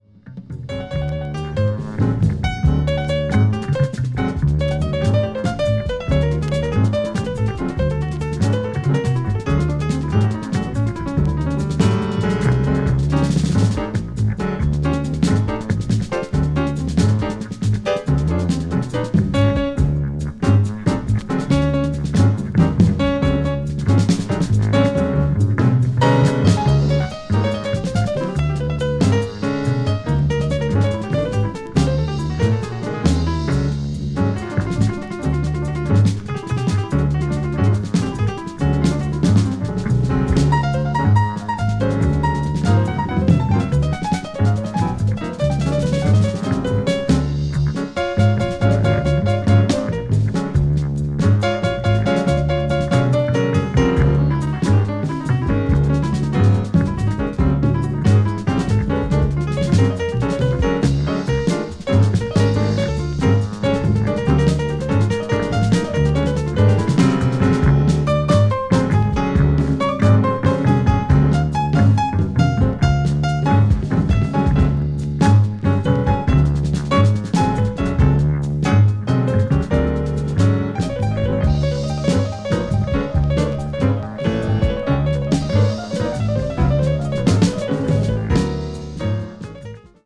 contemporay jazz   crossover   fusion   jazz groove